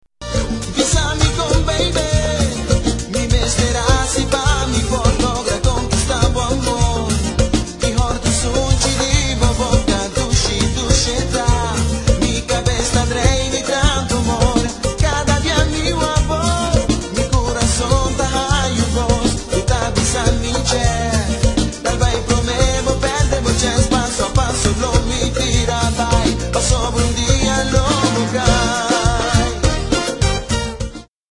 Here’s a recording of part of song in a mystery language.
The music sounds kinda Caribbean-ish… could it be Papiamento? spoken in Curacao if I remember correctly.